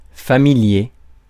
Ääntäminen
IPA: [fa.mi.lje]